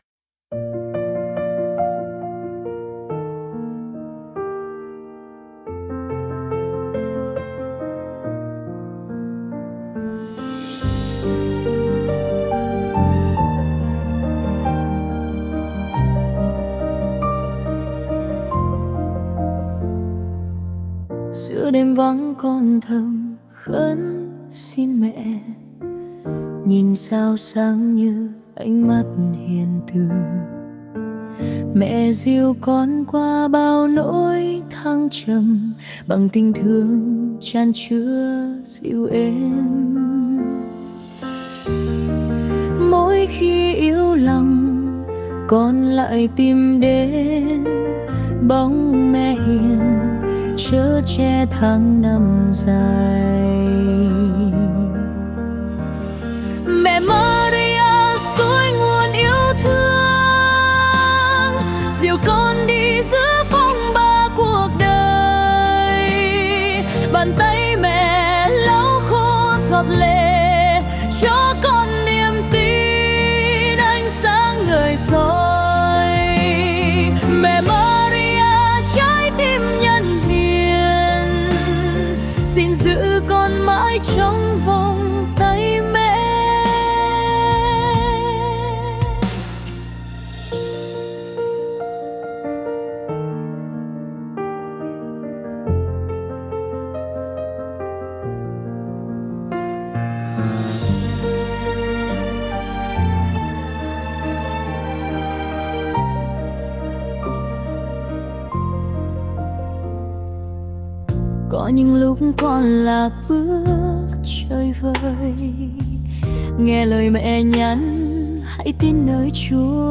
Thánh Ca